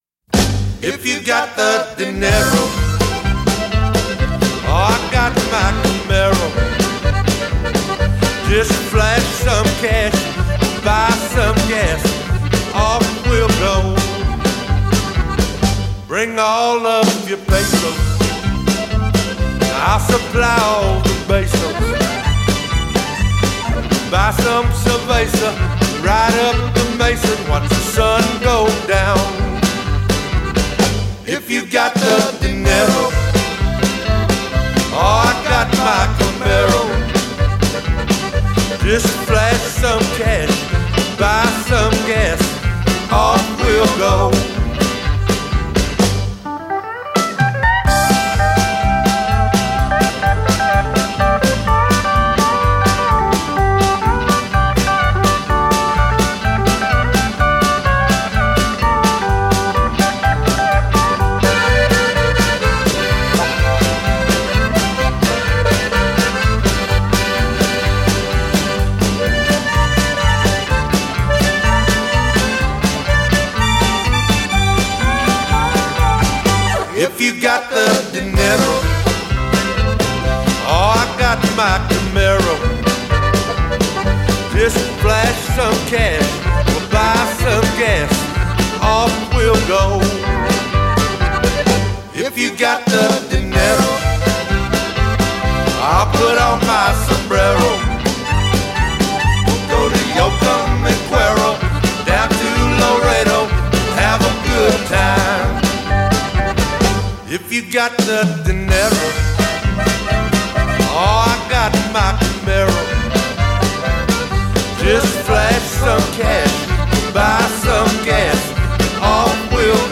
an unabating party atmosphere pervades.